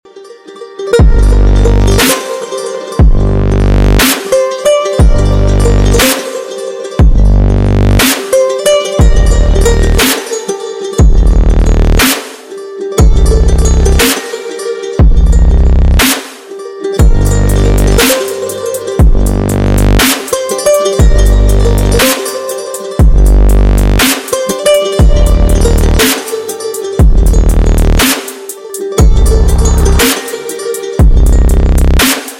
Рингтоны без слов
Спокойные рингтоны , Рингтоны техно
Мощные басы
Trap